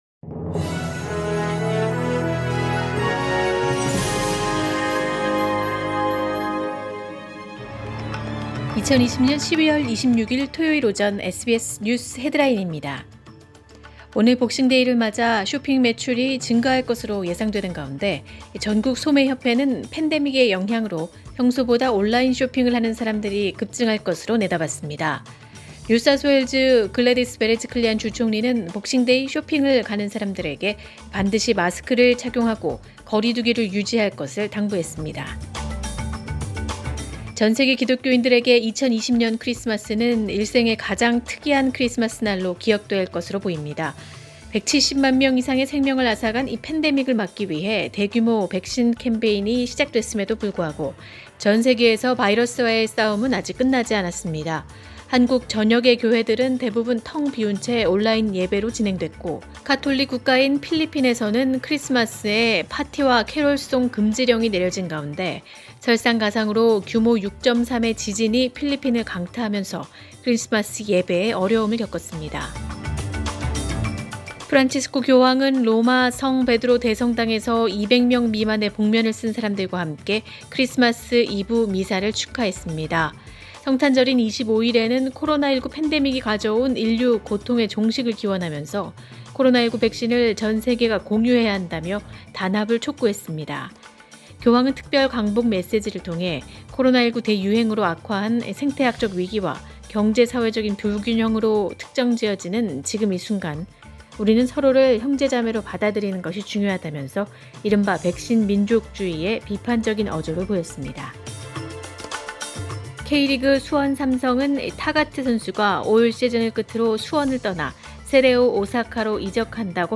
2020년 12월 26일 토요일 오전의 SBS 뉴스 헤드라인입니다.